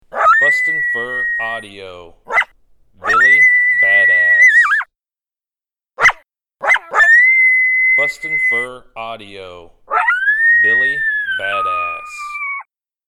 Pair of Coyotes bickering over dominance, great sound to use after locating a pack of coyotes.